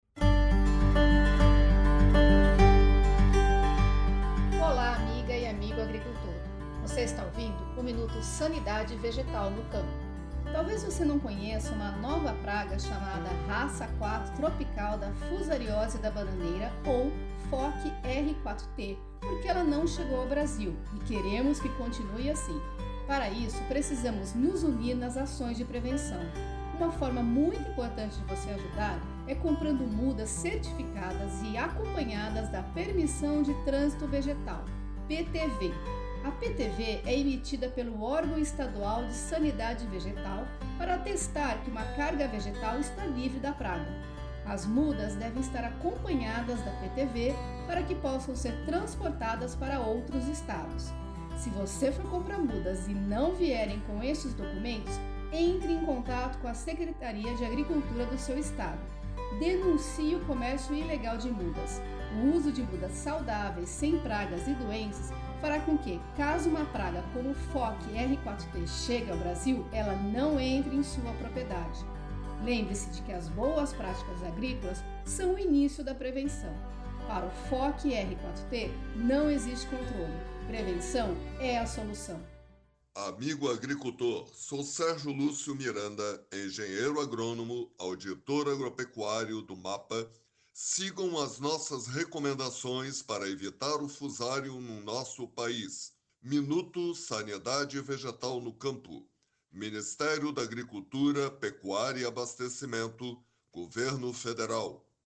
Mensagem de voz 5